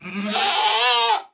Minecraft Version Minecraft Version snapshot Latest Release | Latest Snapshot snapshot / assets / minecraft / sounds / mob / goat / screaming_pre_ram4.ogg Compare With Compare With Latest Release | Latest Snapshot
screaming_pre_ram4.ogg